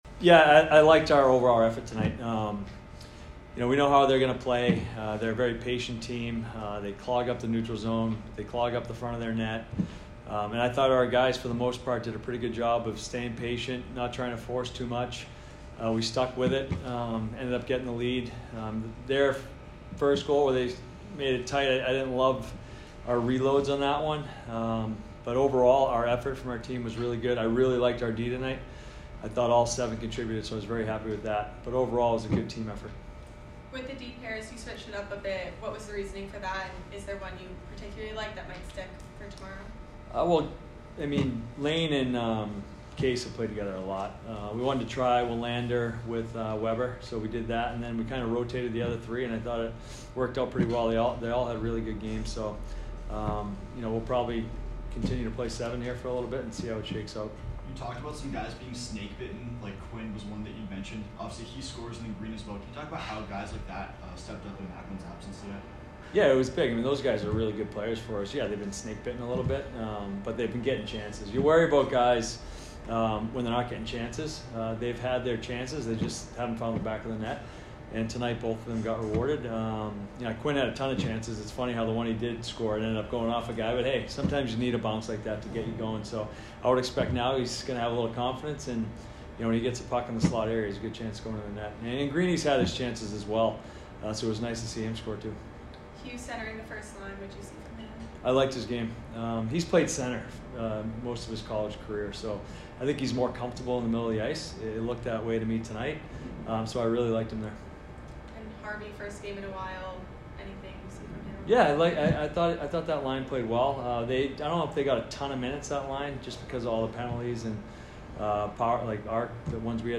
Men's Ice Hockey / UMass Lowell Postgame Interview (11-10-23)